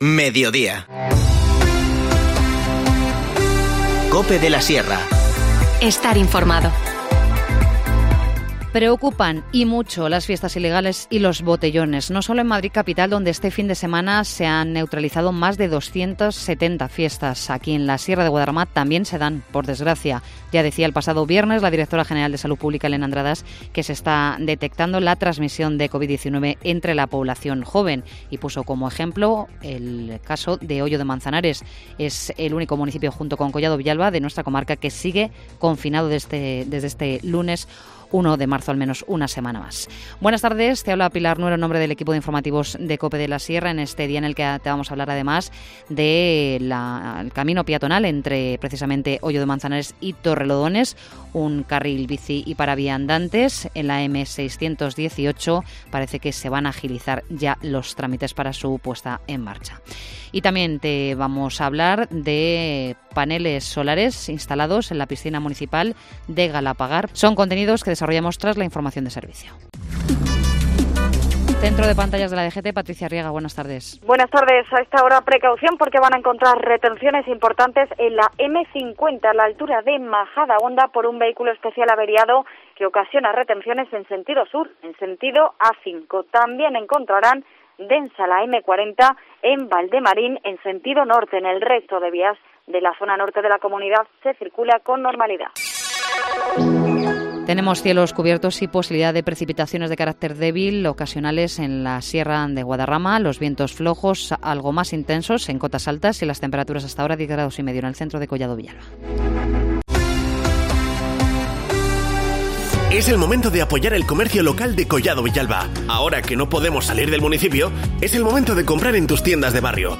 Informativo Mediodía 1 marzo